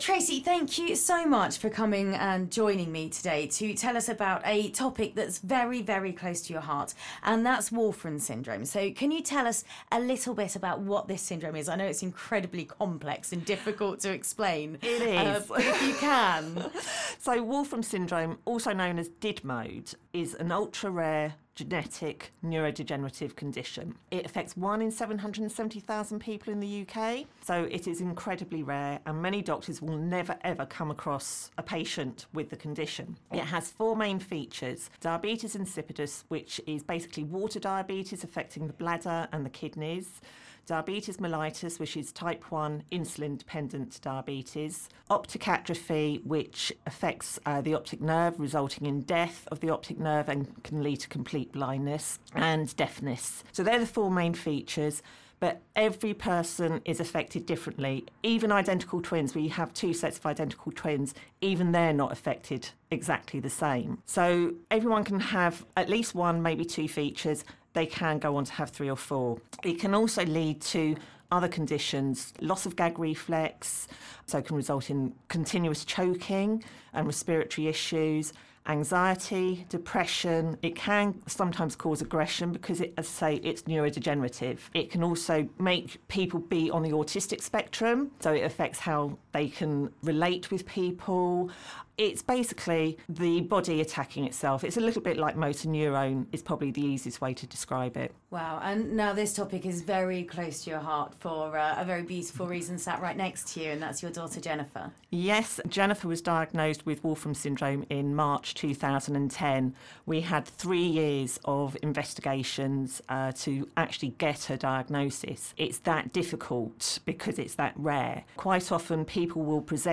WSGAD_V2_radio_interview.mp3